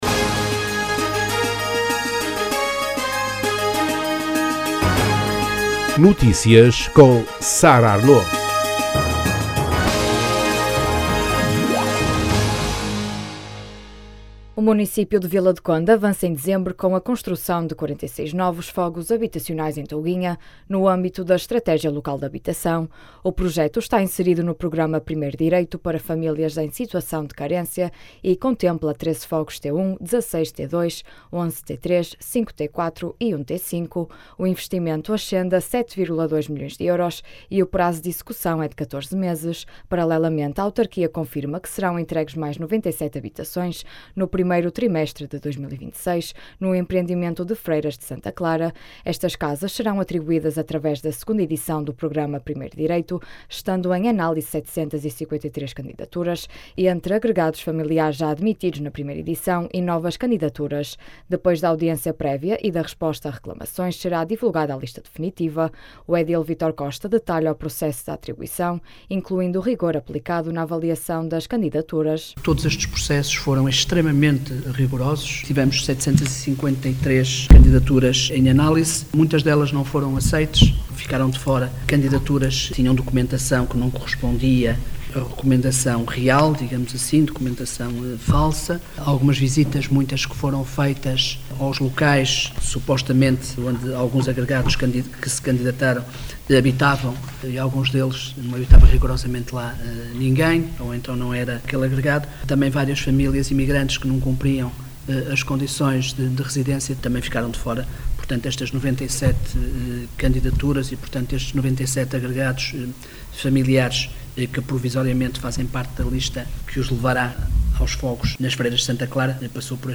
O edil Vítor Costa detalha o processo de atribuição, incluindo o rigor aplicado na avaliação das candidaturas.
As declarações podem ser ouvidas na edição local.